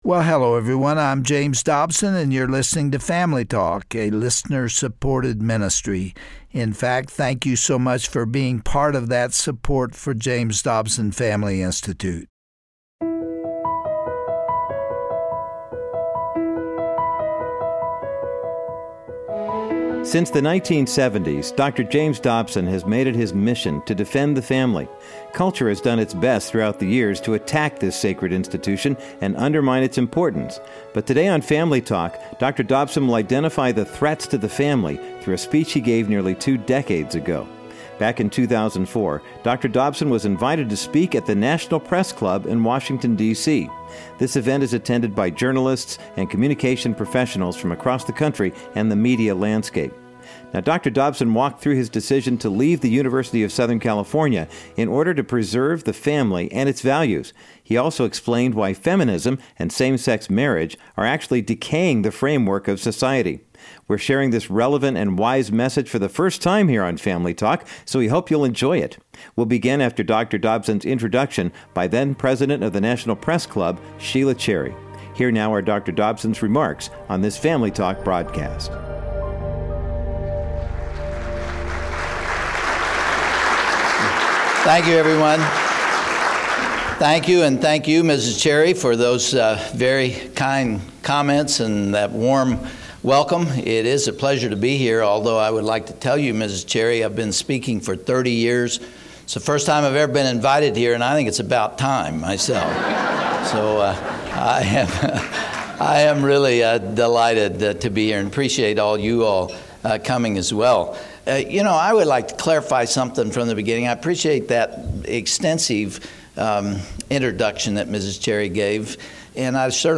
On this classic edition of Family Talk, you'll hear Dr. Dobson address these threats through a speech he gave to the National Press Club back in 2004. He explained his calling to support and defend the family and highlighted the many wayward policies which are negatively impacting this biblical institution.